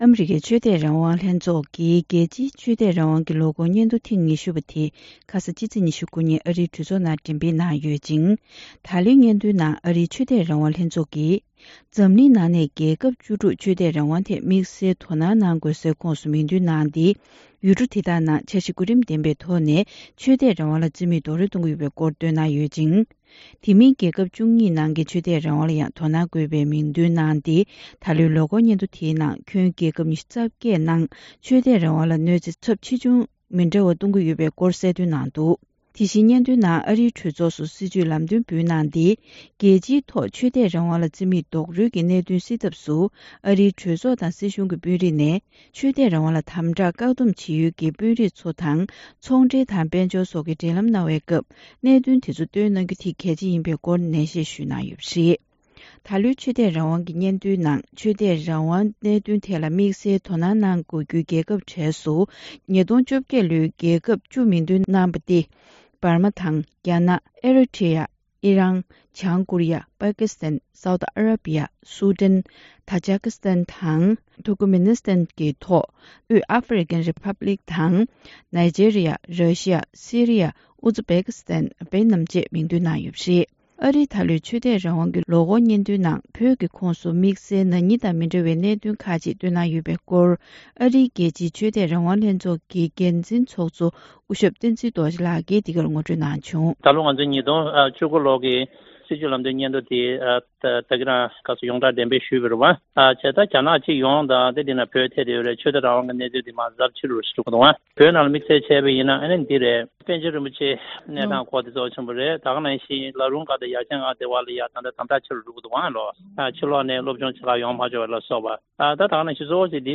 གསར་འགྱུར་དང་འབྲེལ་བའི་ལེ་ཚན་ནང་།